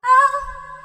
Vox (Vibez).wav